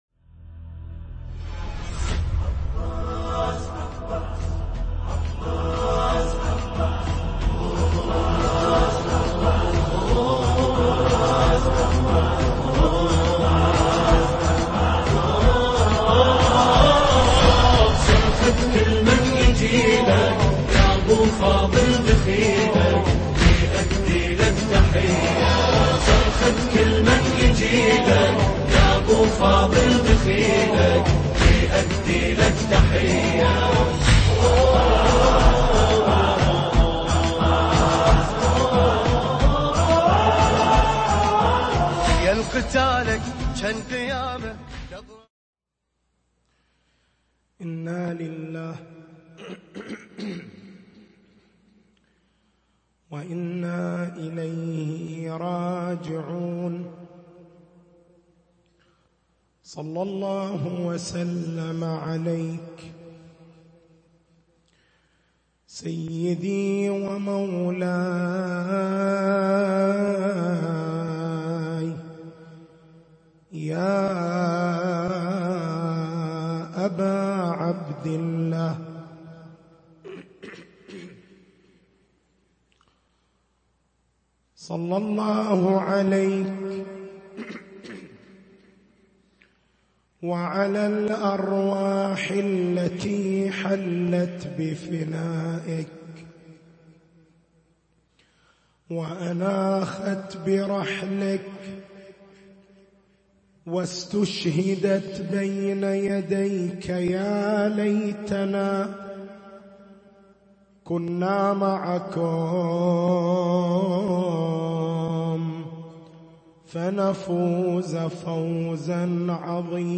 تاريخ المحاضرة: 05/01/1440 محور البحث: كيف يحافظ الإنسان المؤمن على دينه في زمن الشبهات والفتن؟